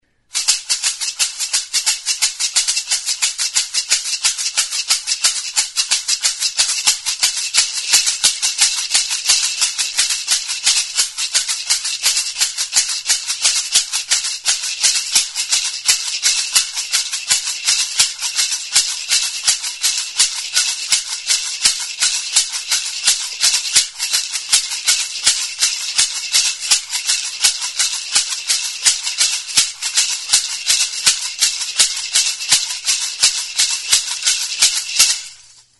Instrumentos de músicaSonajeroa
Idiófonos -> Golpeados -> Maracas / sonajeros
Grabado con este instrumento.
Barnean haziak dituen kalabaza da, zurezko kirtenarekin.
Astintzerakoan hotsa ematen dute hazi aleek eta txindek.